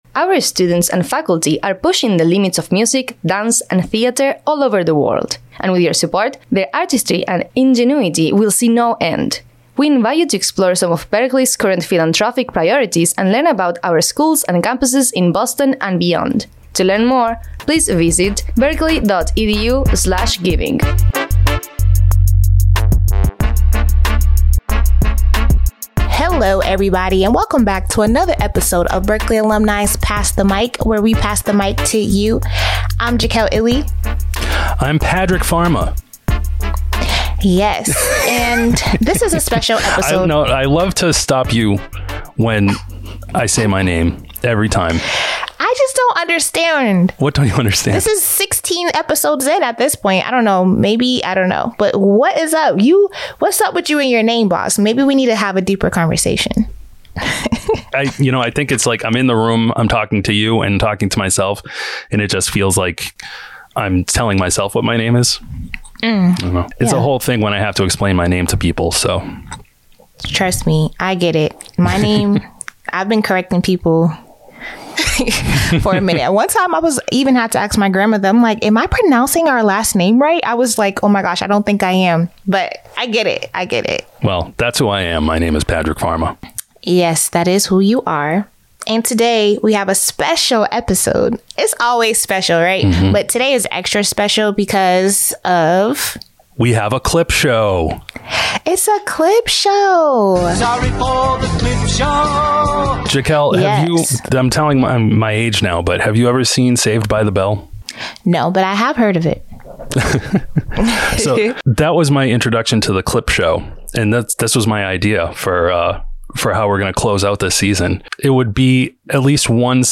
We interview Chandrika Tandon, former Berklee Trustee, Grammy Award-nominated artist, and trailblazing philanthropist. Chandrika talks about the journey of creating her latest album, Ammu's Treasures, and the importance of music in changing the world.